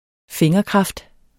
Udtale [ ˈfeŋʌˌkʁɑfd ]